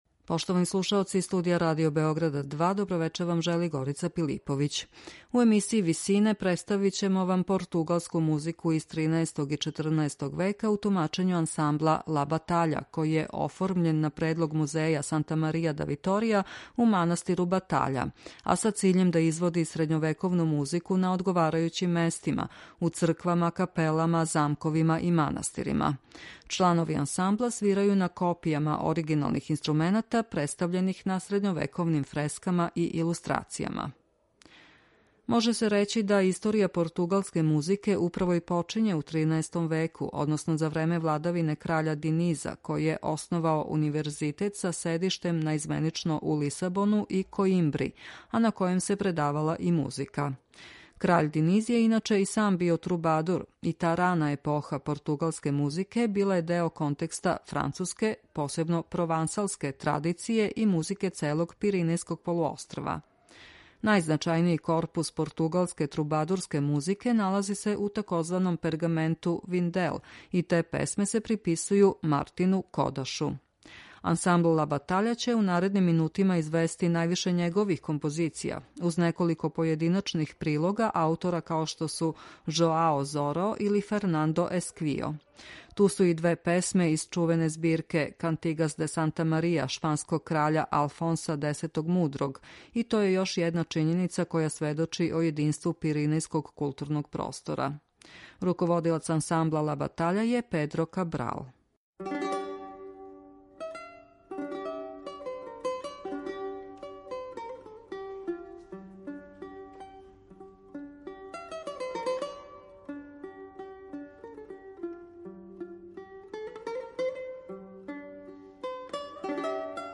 У вечерашњој емисији Висине можете слушати песме из средњег века у извођењу португалског ансамбла Ла батаља.
Стара португалска музика
Ансамбл Ла батаља основан је у истоименом манастиру с циљем извођења средњовековне музике на одговарајућим местима - у црквама, капелама, замковима и манастирима. Чланови ансамбла свирају на копијама оригиналних инструмената представљених на средњовековним фрескама и илустрацијама.